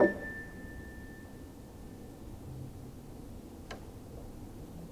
healing-soundscapes/Sound Banks/HSS_OP_Pack/Upright Piano/Player_dyn1_rr1_036.wav at d9198ae2b96f66d03c5143cfed161a08545b35dc